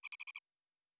pgs/Assets/Audio/Sci-Fi Sounds/Interface/Data 20.wav at master